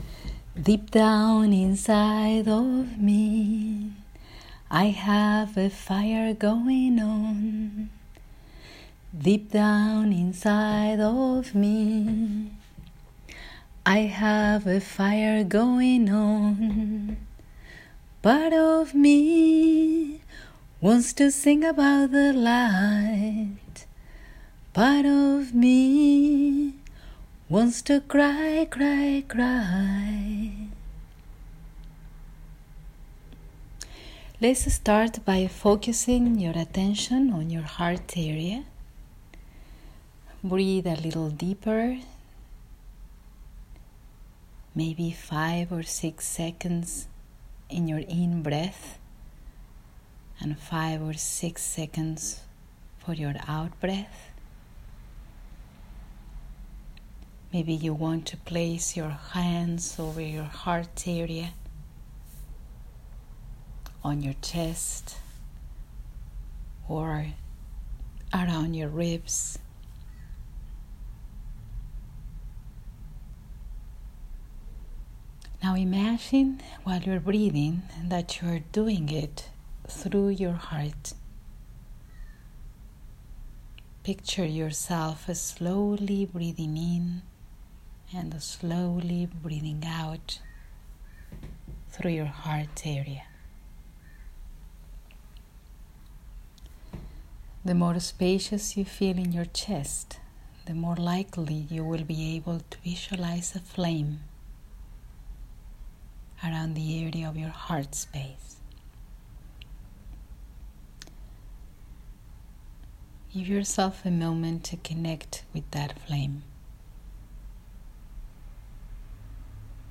fire-within-meditation.m4a